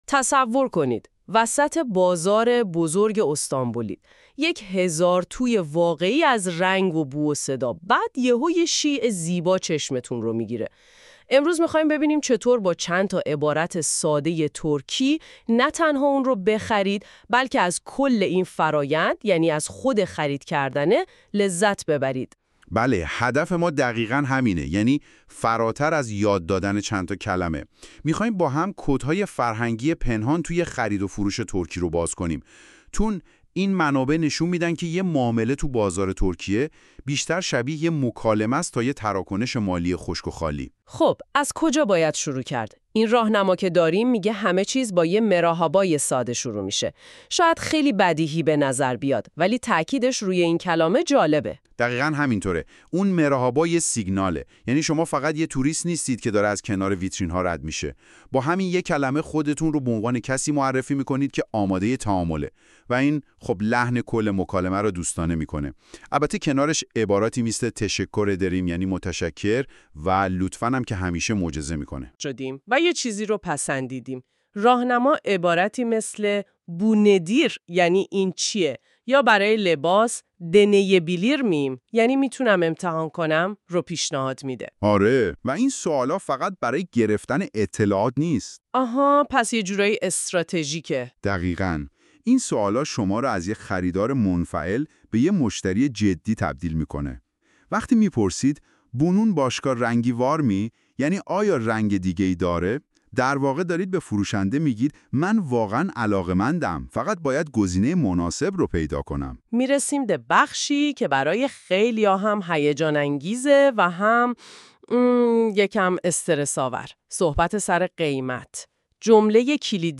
turkish-shopping-conversation.mp3